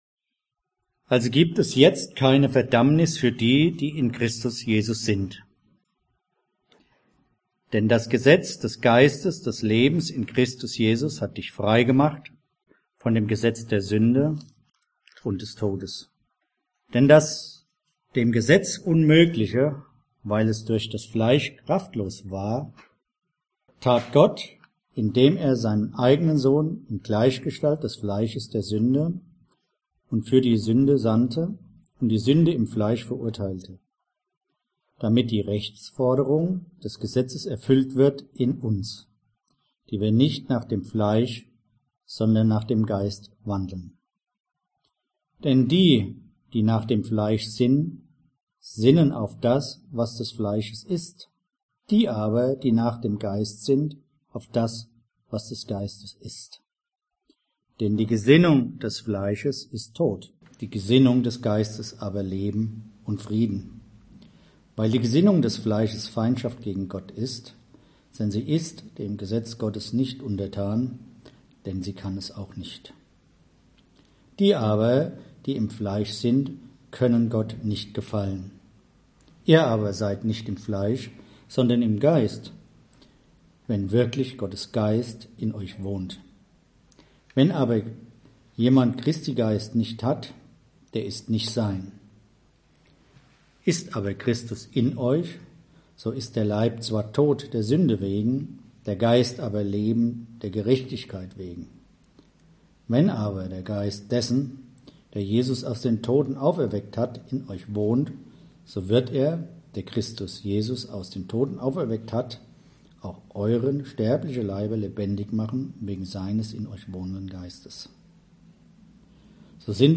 Audio Predigt | Christusgemeinde Frankfurt